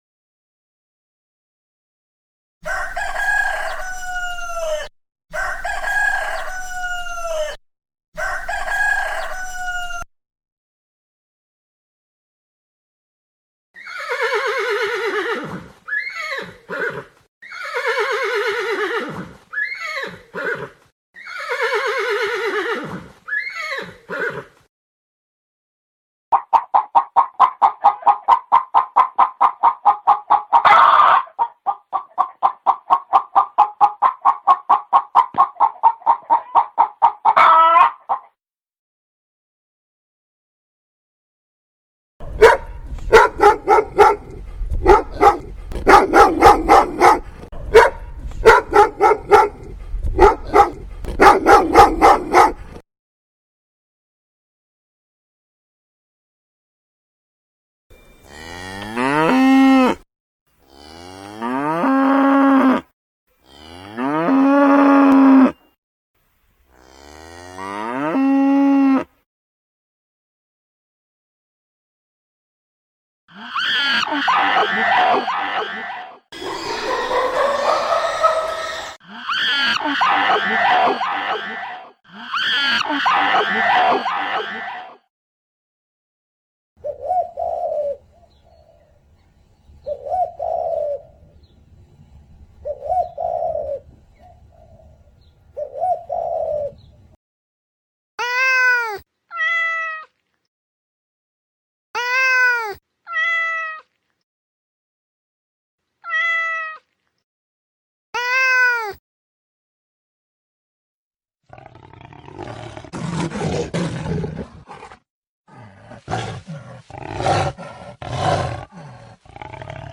ruidos_animales.mp3